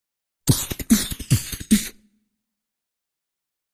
Human Beat Box, Fast, Simple, Rhythm, Type 2 - Short